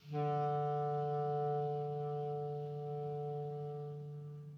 DCClar_susLong_D2_v1_rr1_sum.wav